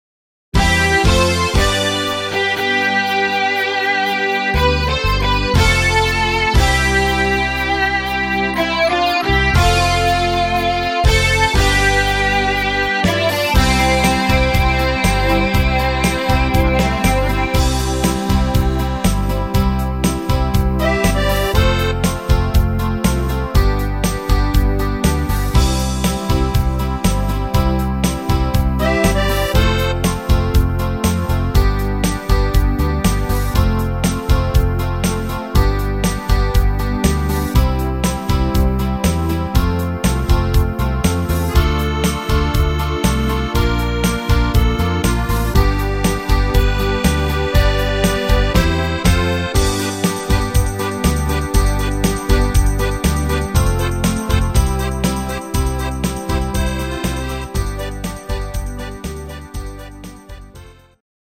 Rhythmus  Light Rock
Art  Volkstümlich, Deutsch